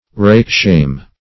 Search Result for " rakeshame" : The Collaborative International Dictionary of English v.0.48: Rakeshame \Rake"shame`\ (r[=a]k"sh[=a]m`), n. [Cf. Rakehell , Ragabash .]